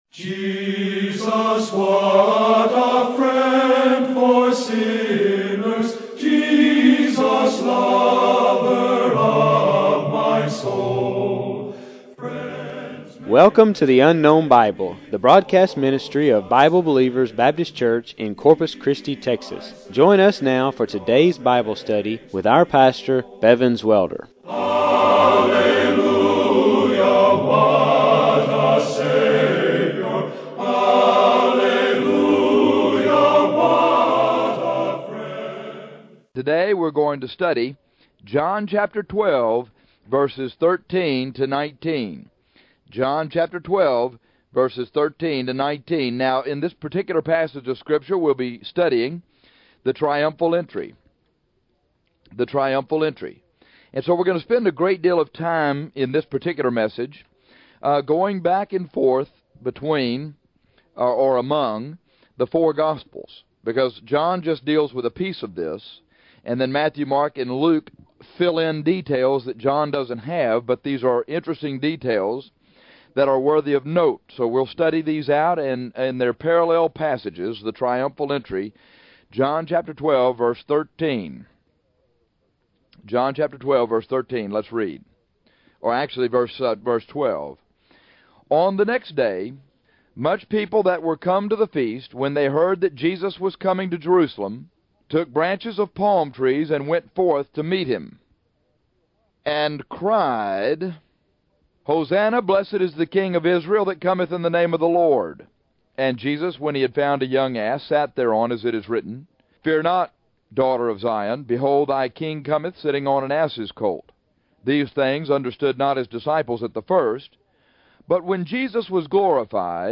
The four gospels record different details concerning the triumphal entry. This radio broadcast harmonizes those details and gives you interesting truths.